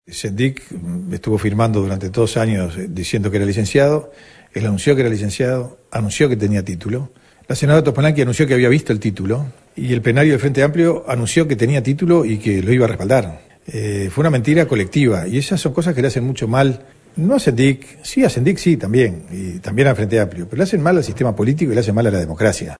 En declaraciones a Telemundo, el senador colorado José Amorín consideró que todo se trató de una mentira:
5-AMORIN-sobre-Sendic.mp3